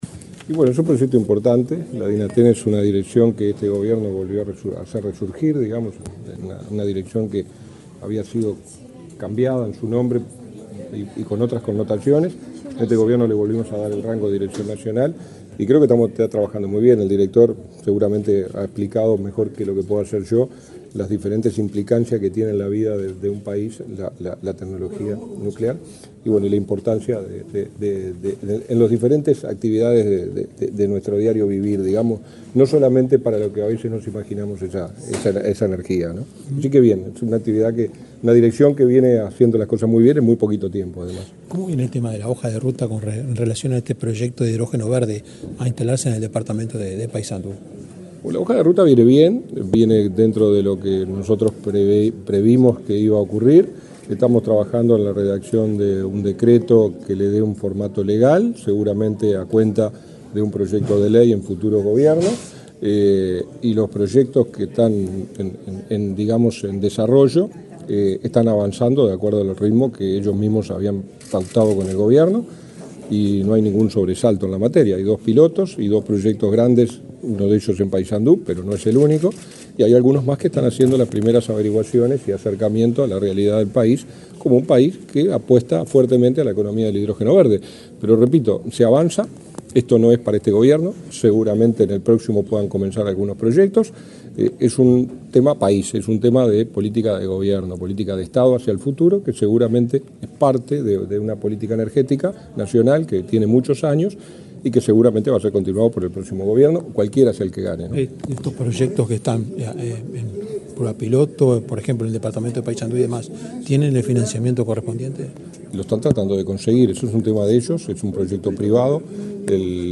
El subsecretario de Industria, Energía y Minería, Walter Verri, dialogó con la prensa, este lunes 22 en la Torre Ejecutiva, antes de participar en un